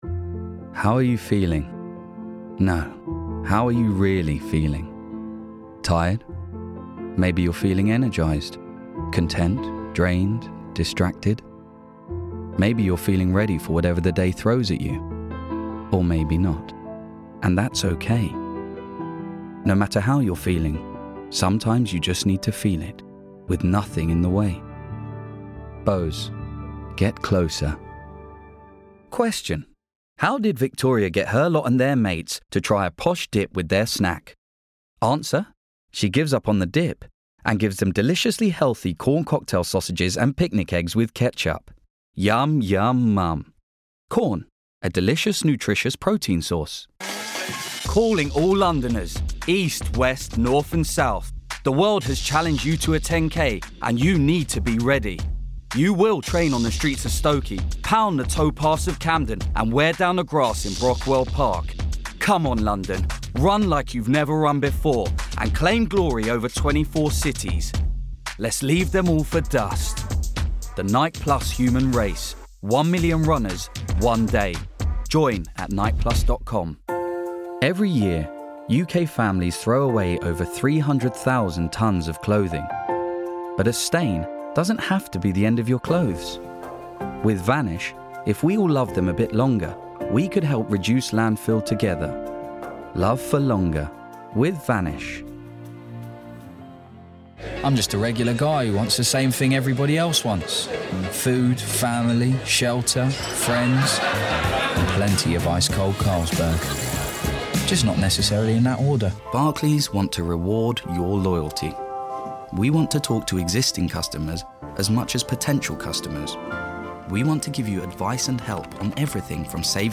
LONDON / STREET / RP / ASIAN.
His voice range is late teens through to 30s, and deliveries from London urban to RP, as well as conversational Punjabi and some Middle & Far Eastern accented-English.